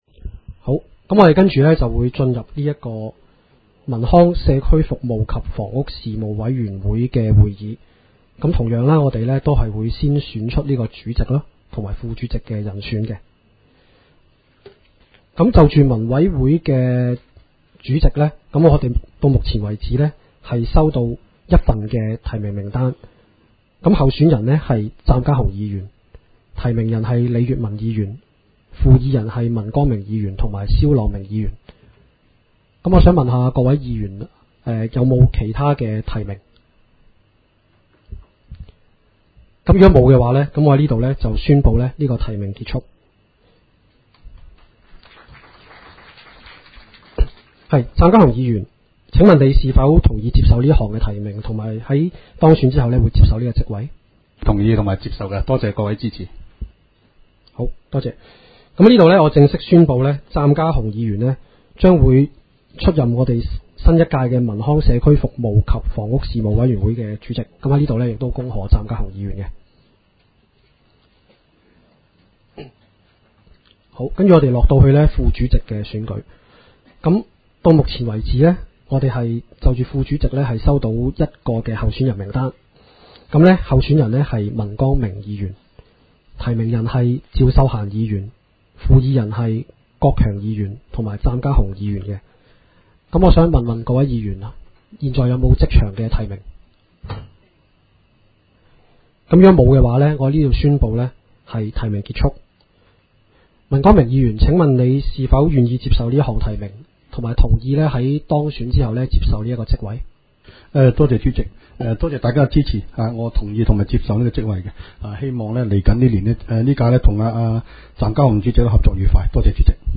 委员会会议的录音记录
地点: 元朗桥乐坊2号元朗政府合署十三楼会议厅